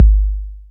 DB - Kick (7).wav